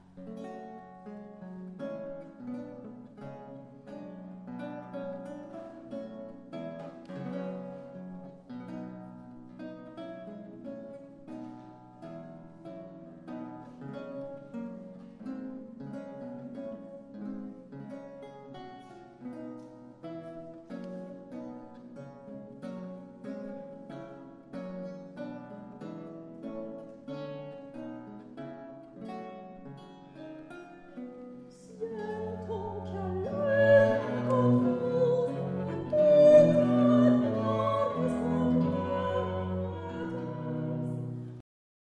La musique baroque vivante !
L'Archiluth
Enregistré à l'église d'Arberats (64120) le 24 Juillet 2012
archiluth-tonada-arberats-05.mp3